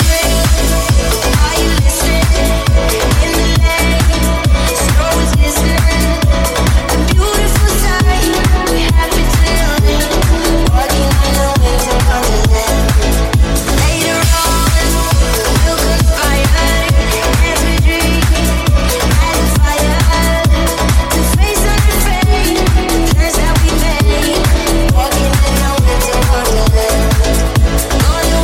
Genere: edm,deep,bounce,house,slap,cover,remix hit